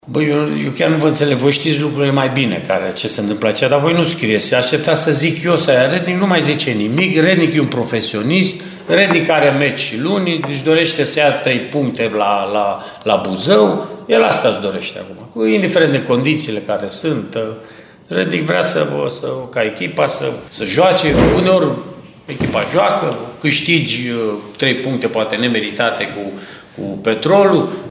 Tehnicianul arădenilor a vorbit mai puțin despre meci la conferința de presă de astăzi.